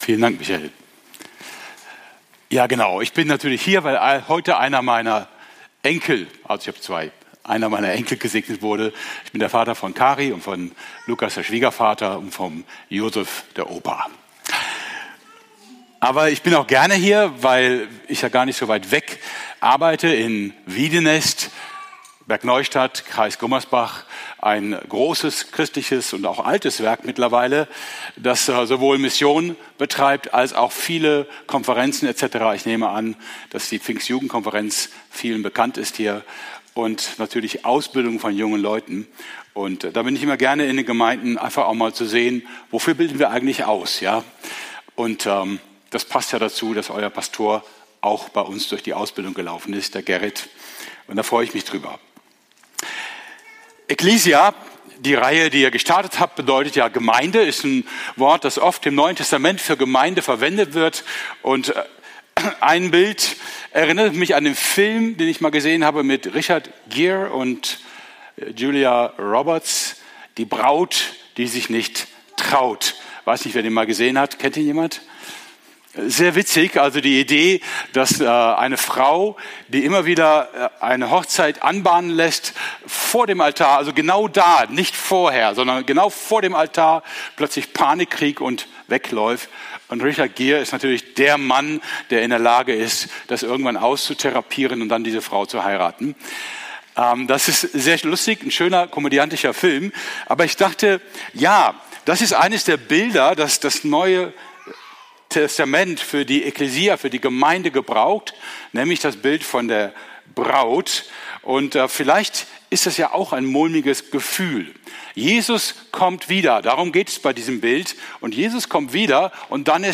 Predigt vom 22.03.2026 in der Kirche für Siegen